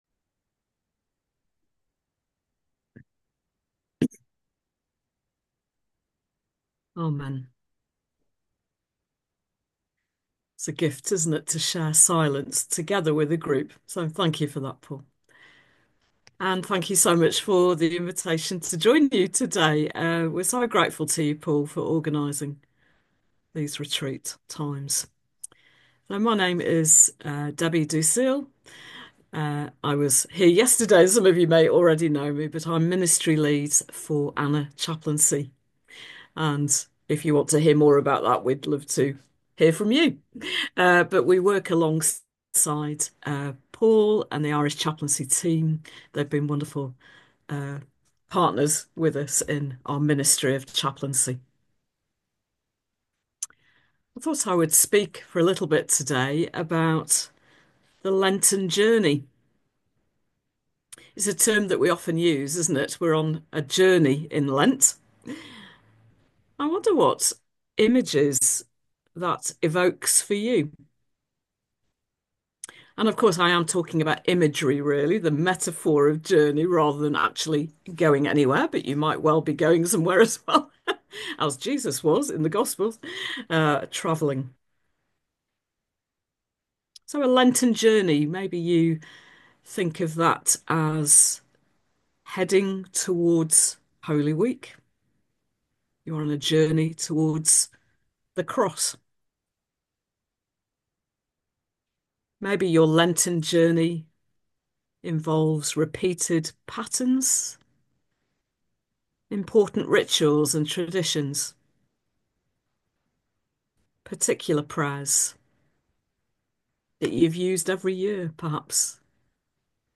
Listen to a Lenten Reflection that was part of Irish Chaplaincy's series of seasonal retreats